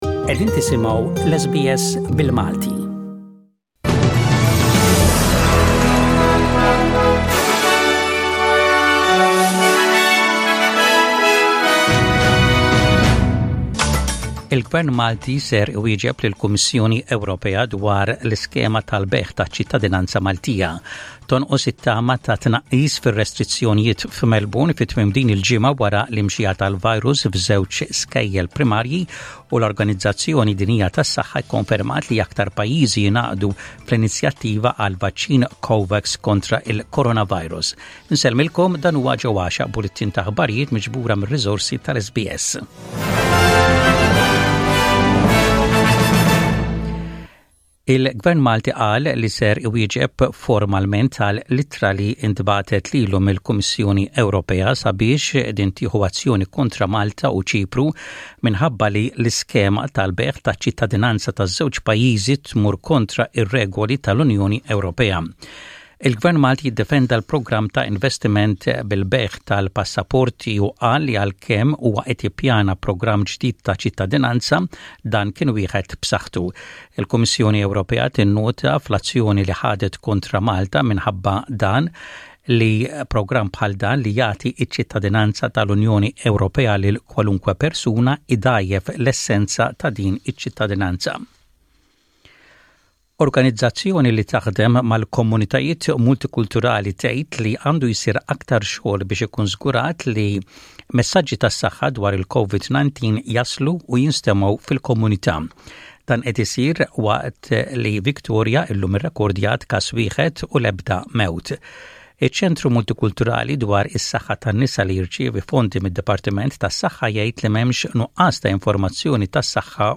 SBS Radio | Maltese News: 23/10/20